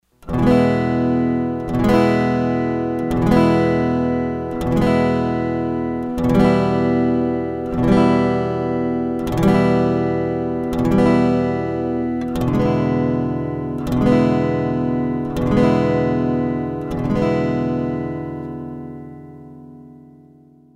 Chord Quality
In this course, you will learn to play chords with three different qualities: major, minor, and dominant seventh.
Listen to the chords as they change.
2.04playchordqualityEE.mp3